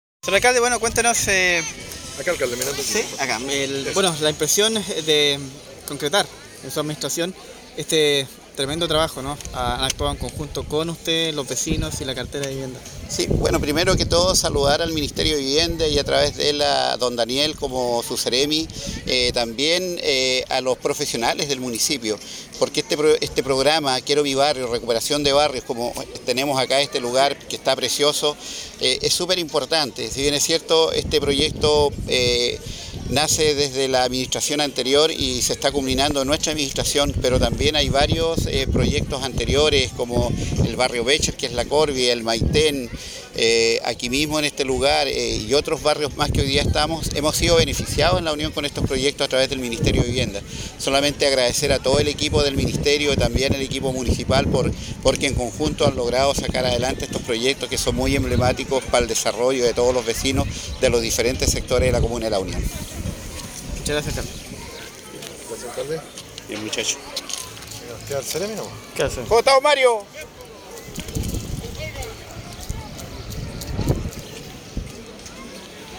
alcalde-Andres-Reinoso-inauguracion-polideportivo.mp3